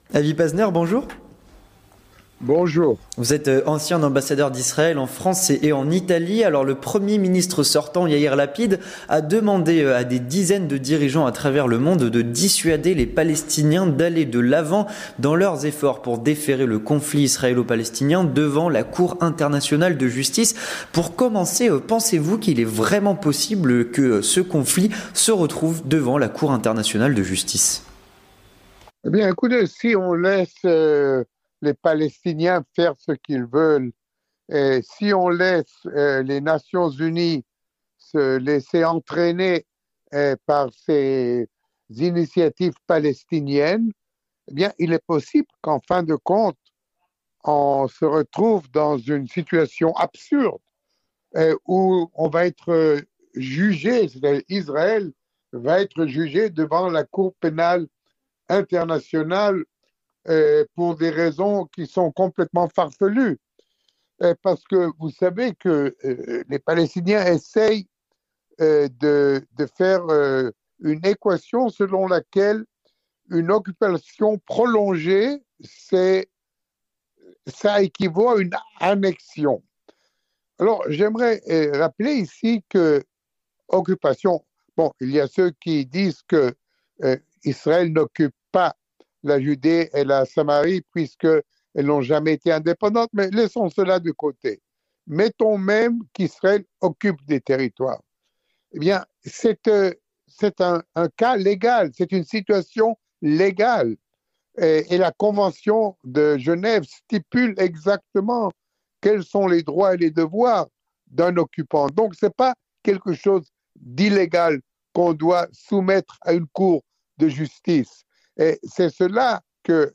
L'Entretien du Grand Journal, avec Avi Pazner, ancien ambassadeur d'Israël en France et en Italie.